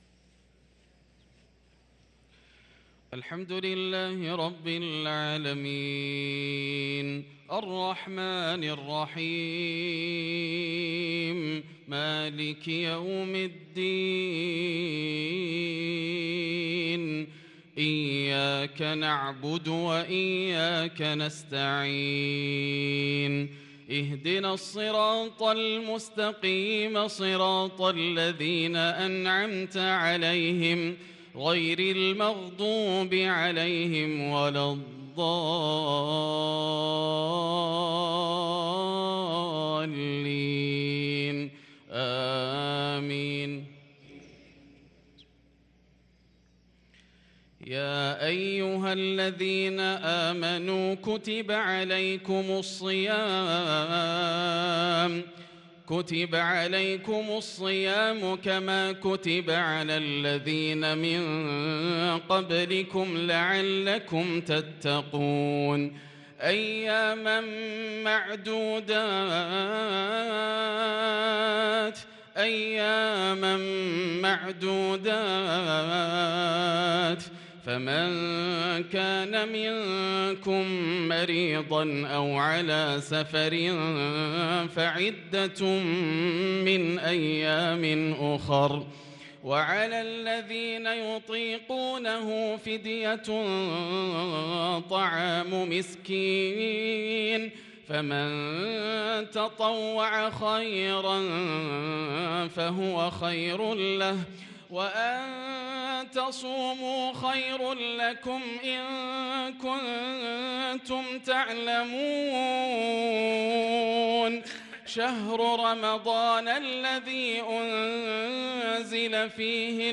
صلاة المغرب للقارئ ياسر الدوسري 29 شعبان 1443 هـ
تِلَاوَات الْحَرَمَيْن .